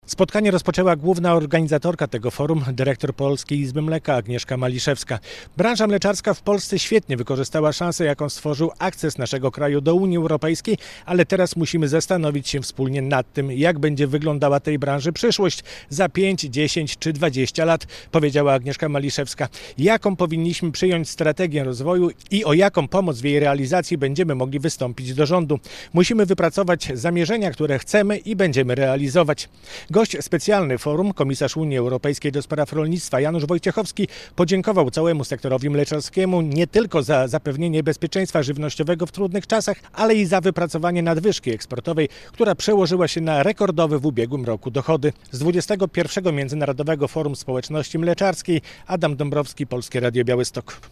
Forum Mleczarskie w Białymstoku - relacja